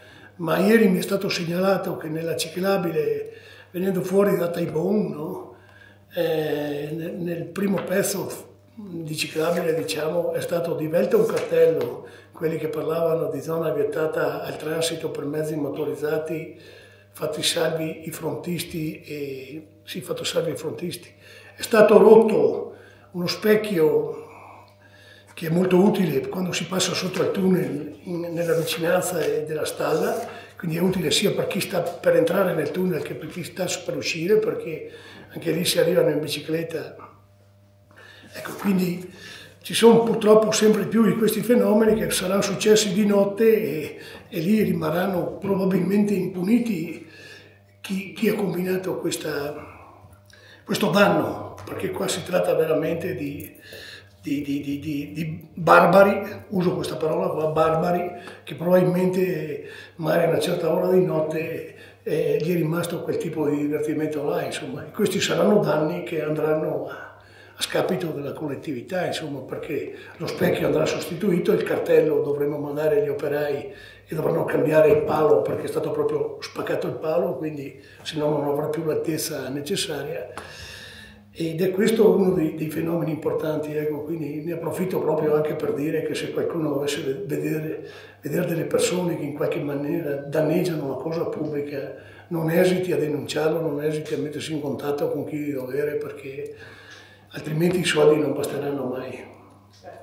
AGORDO – Un altro episodio di vandalismo, lungo la ciclabile Bries-Polane, diveto il cartello, distrutto l’utile e indispensabile “specchio”. Il sindaco Roberto Chissalè, durante il consiglio comunale trasmesso in diretta ieri sera da Radio Più, ha preso una posizione netta contro gli autori, ancora ignoti, definendoli senza esitazione “barbari”.
L’intervento del primo cittadino è stato acceso e carico di indignazione.